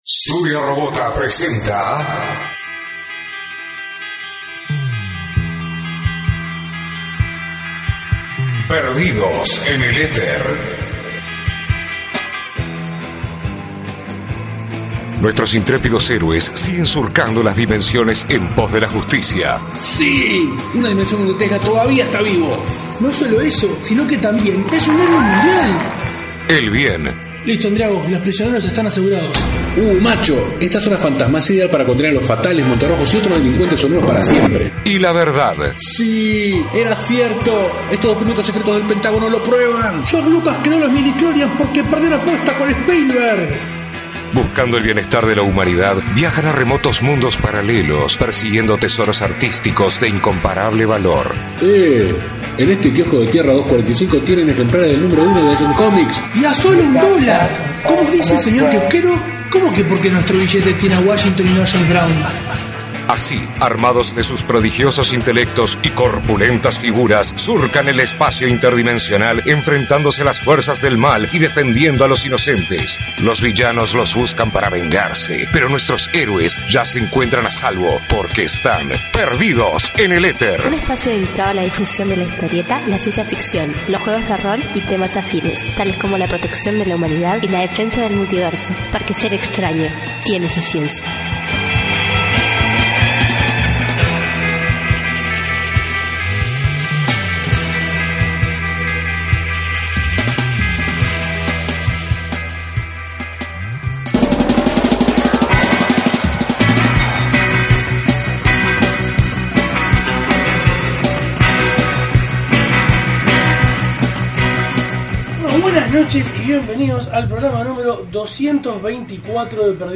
Durante una conversación larga y jugosa, salió una RE: seña mezclada con Mesa Nerdonda, y llenita llenita de SPOILERS.